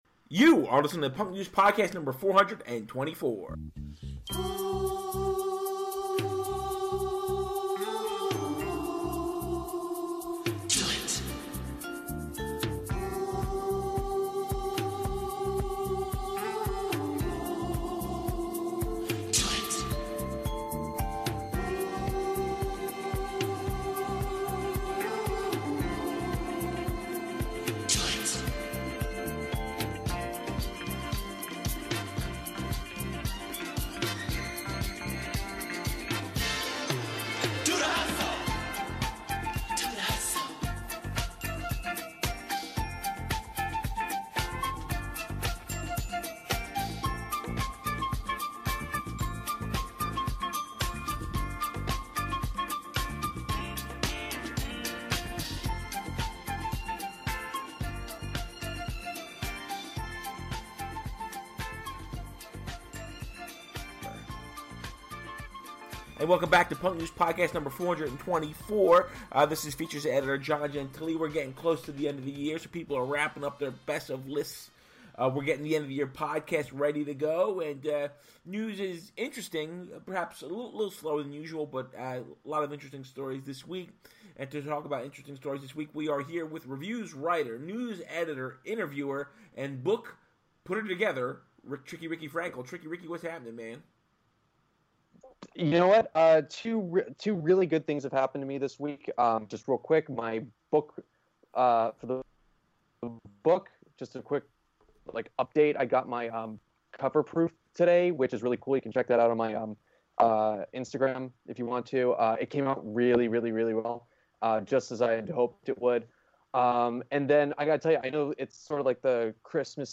Audio issues abound, we're trying folks.